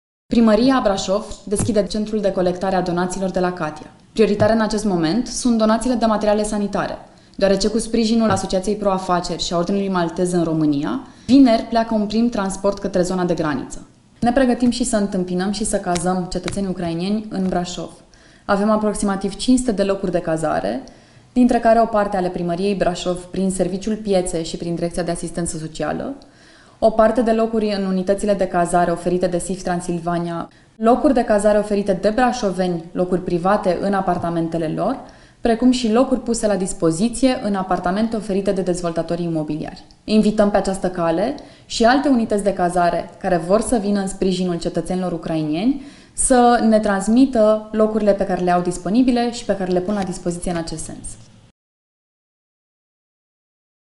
Viceprimarul Flavia Boghiu: